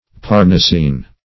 parnassien - definition of parnassien - synonyms, pronunciation, spelling from Free Dictionary Search Result for " parnassien" : The Collaborative International Dictionary of English v.0.48: Parnassien \Par`nas`si`en"\, n. [F.]
parnassien.mp3